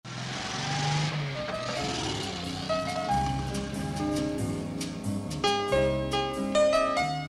Из какого фильма этот аудиофрагмент?